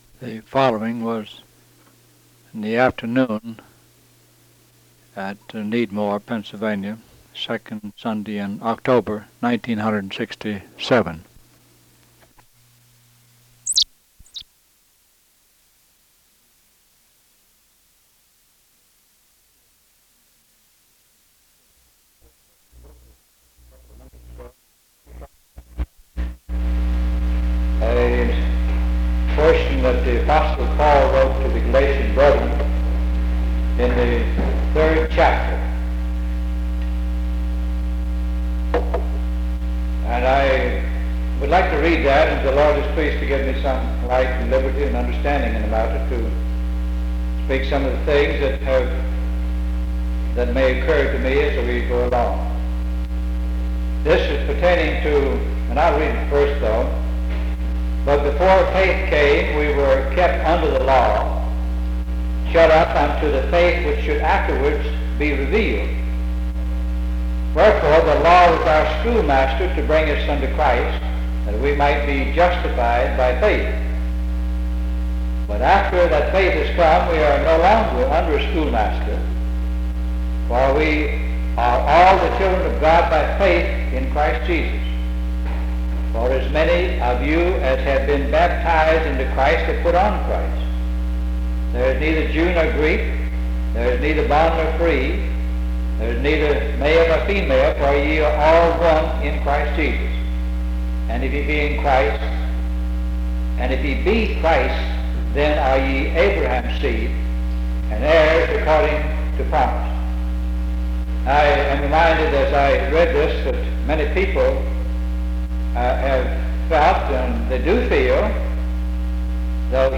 Galatians 3:23-29 (Poor quality recording)